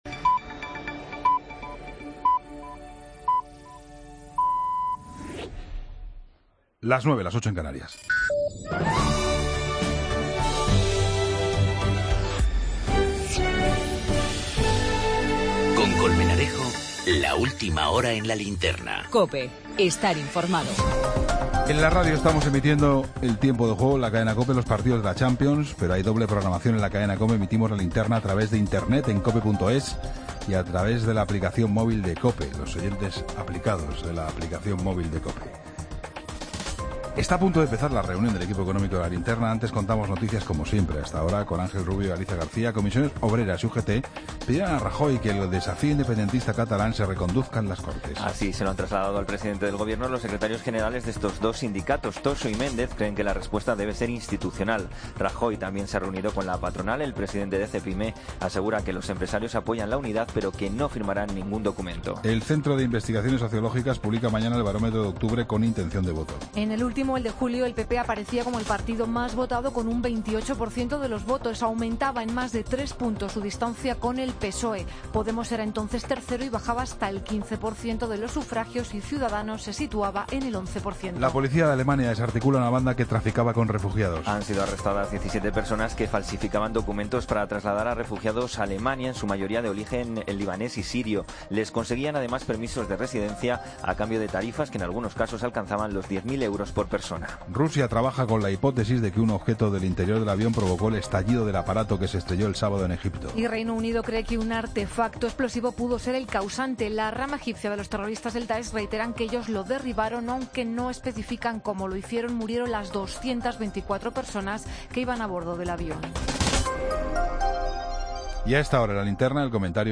La tertulia económica